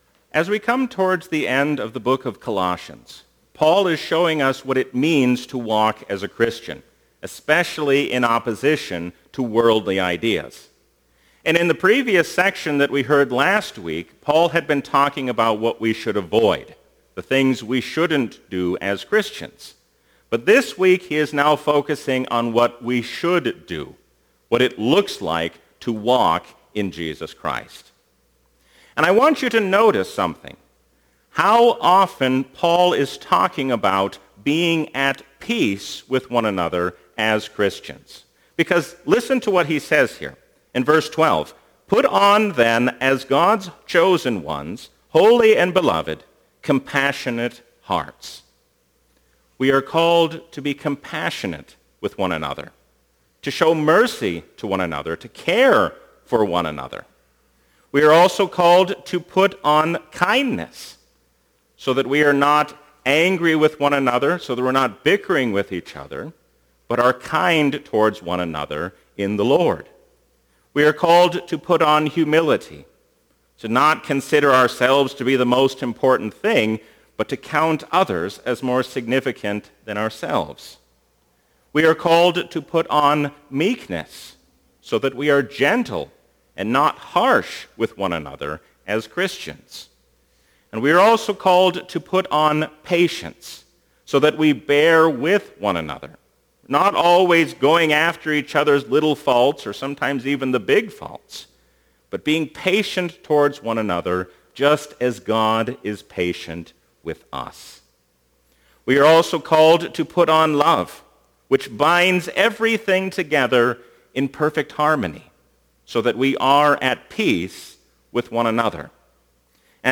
A sermon from the season "Trinity 2021."